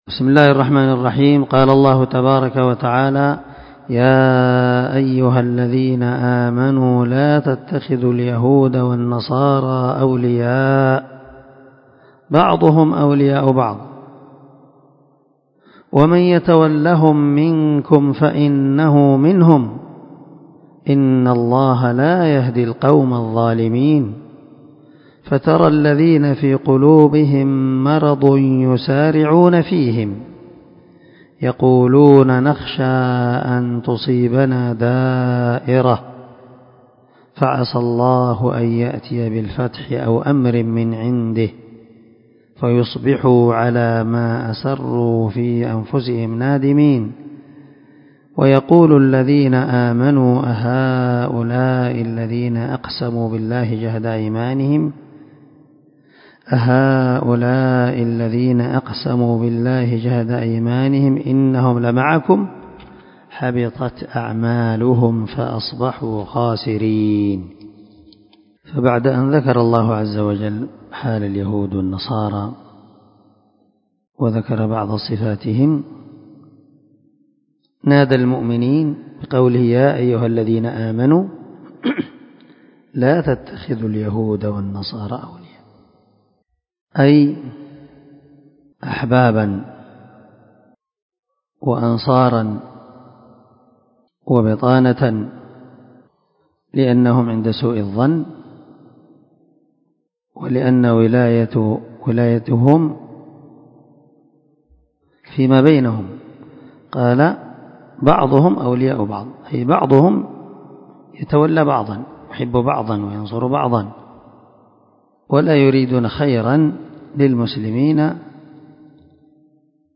368الدرس34 تفسير آية ( 51 – 53 ) من سورة المائدة من تفسير القران الكريم مع قراءة لتفسير السعدي
دار الحديث- المَحاوِلة- الصبيحة.